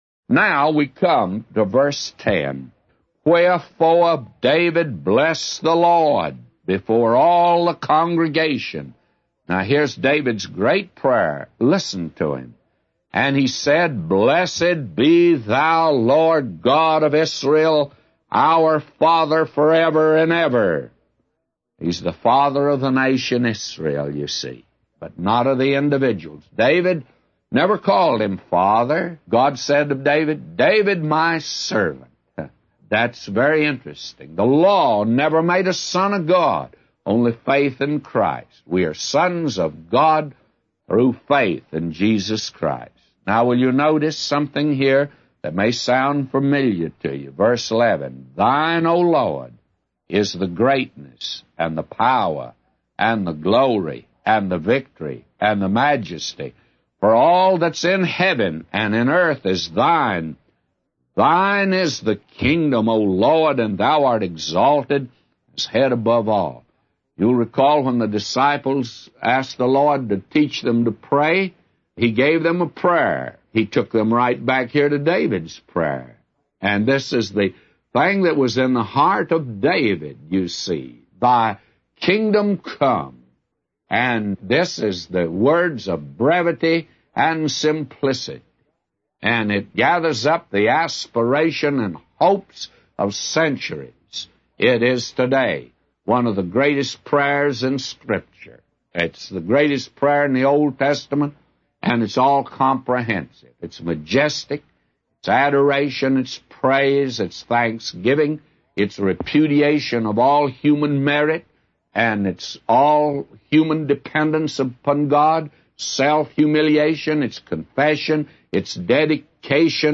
A Commentary By J Vernon MCgee For 1 Chronicles 29:10-999